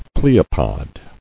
[ple·o·pod]